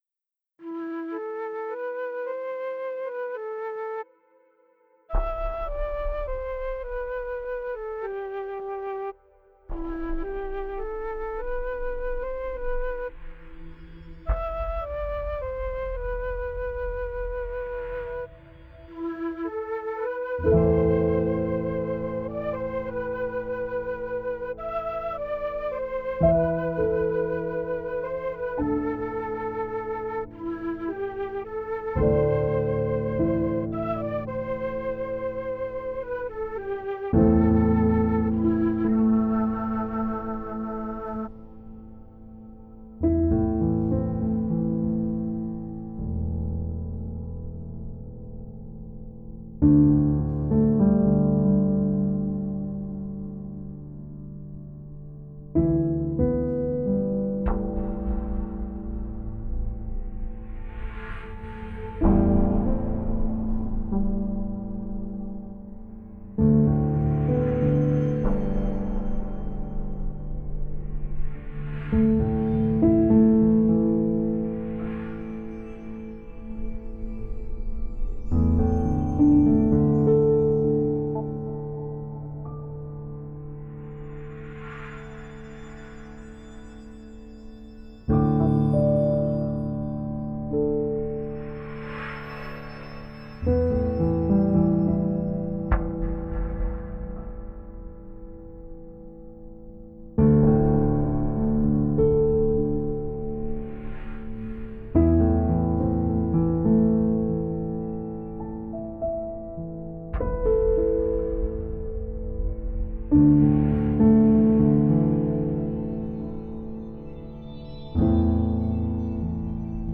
5.1 streaming of the first half
Released November 6, 2017 in 5.1 surround sound.
This is music made for surround sound!